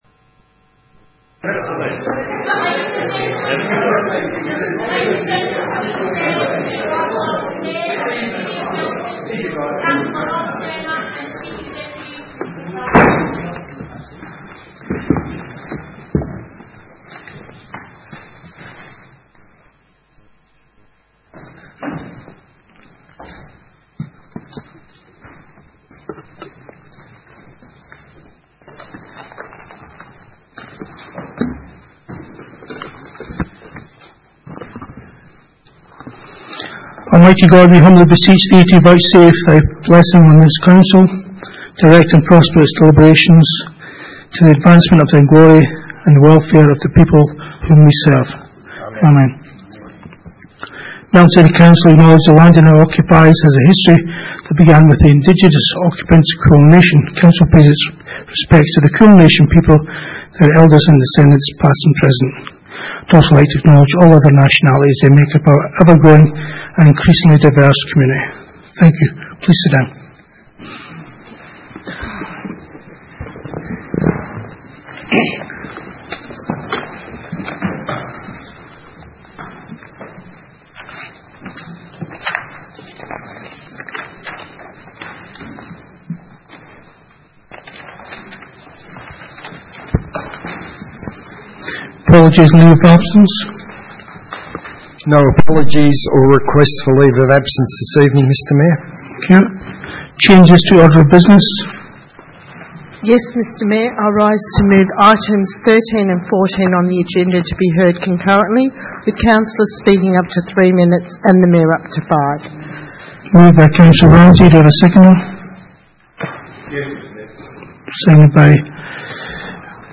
Ordinary Meeting 14 October 2019
Council Chambers, 232 High Street, Melton, 3337 View Map